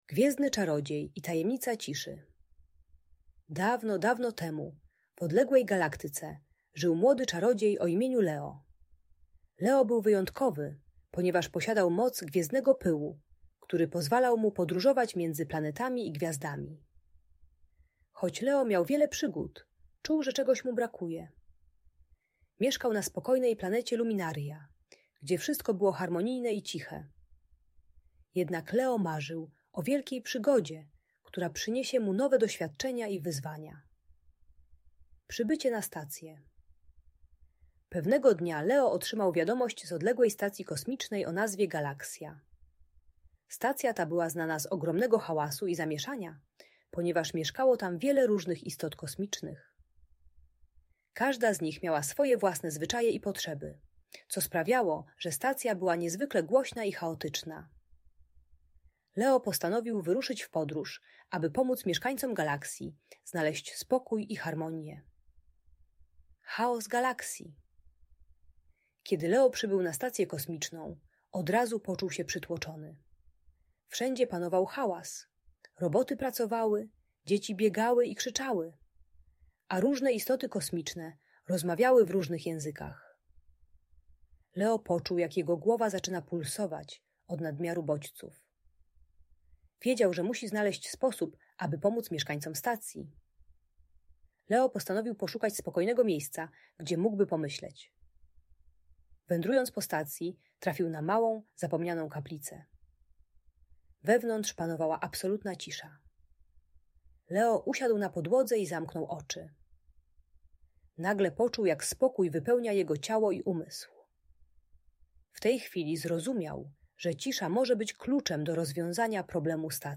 Gwiezdny Czarodziej i Tajemnica Ciszy - Audiobajka